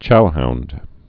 (chouhound)